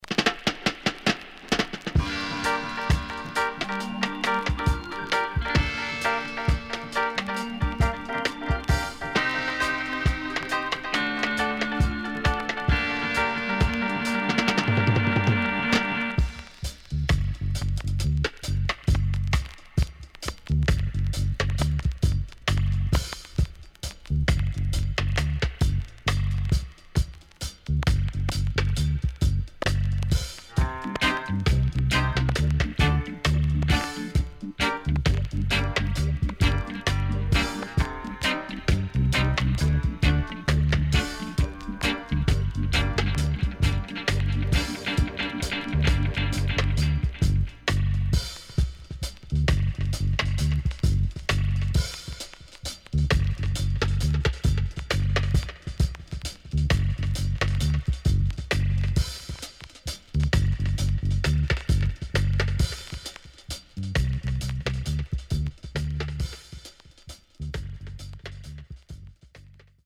Rare.銃声SEから始まるKiller Vocal
SIDE A:所々チリノイズがあり、少しプチノイズ入ります。